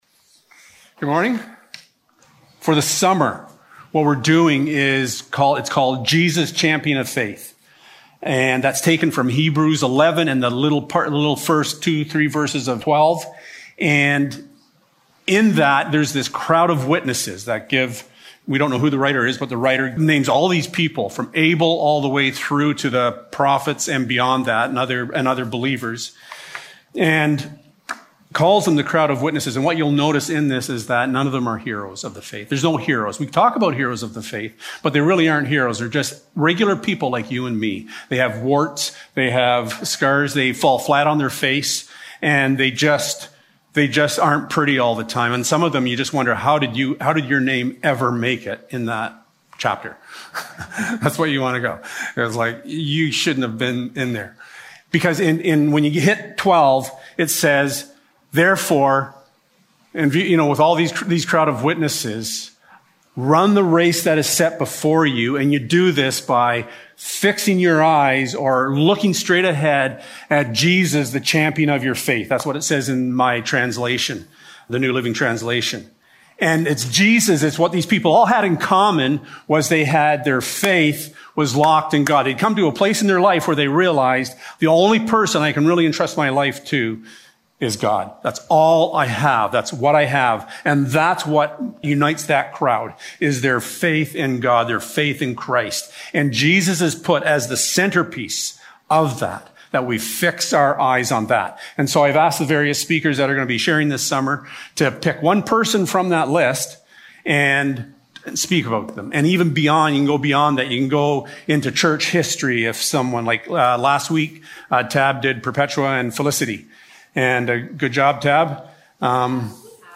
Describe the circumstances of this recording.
Jesus-Champion of Faith Service Type: Sunday Morning O how things go awry when one forgets the essence of who God is.